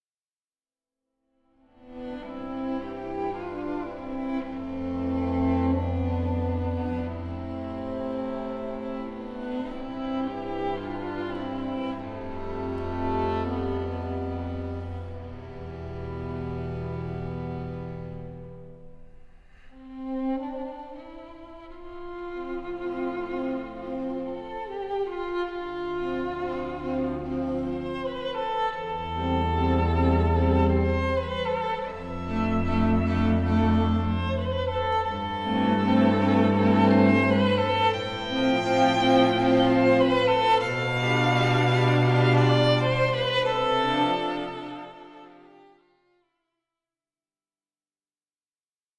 Largo (1.29 EUR)